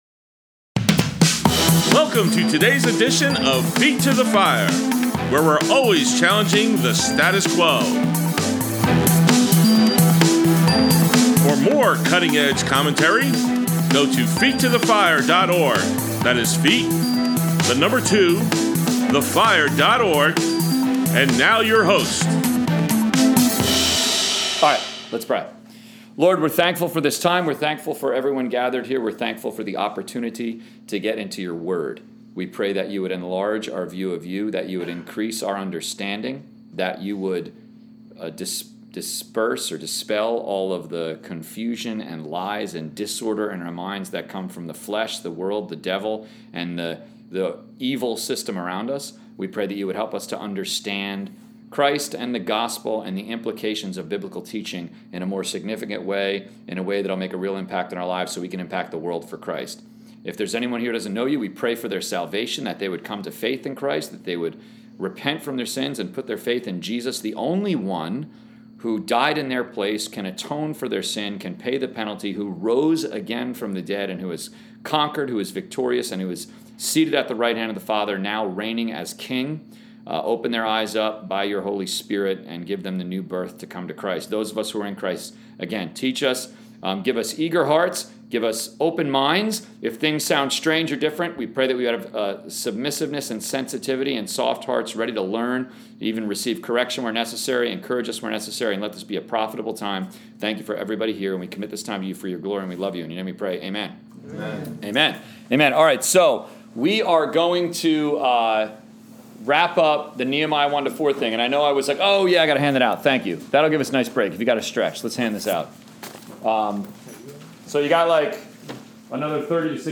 College/Career Bible Study on 10.15.21